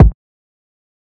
SoufKick.wav